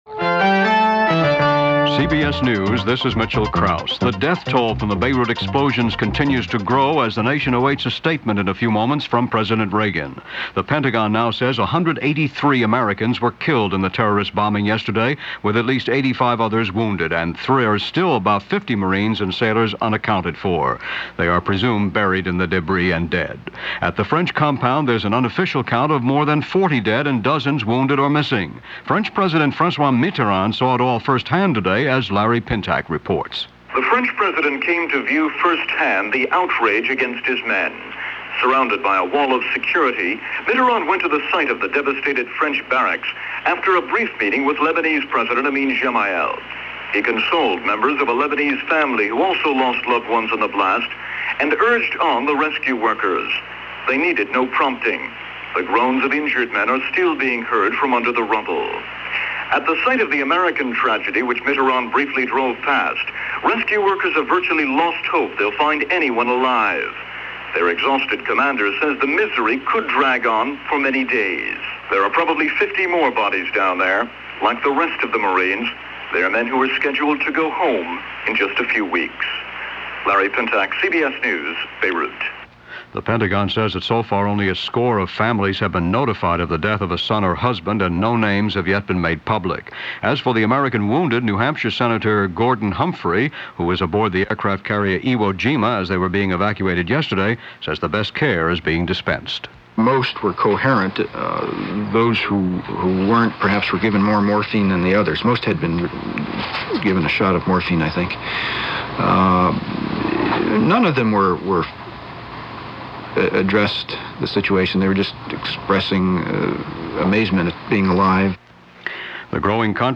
October 24, 1983 - Aftermath of the bombing of the U.S. Marines barracks in Beirut - including a press conference by President Reagan.